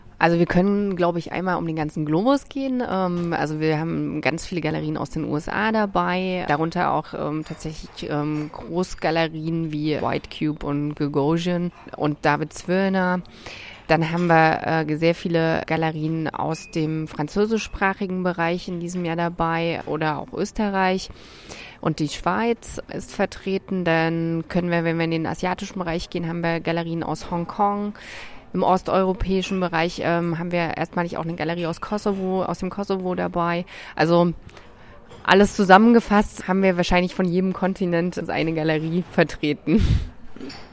Nach der Pressekonferenz stand Sie uns für ein kurzes Interview zur Verfügung: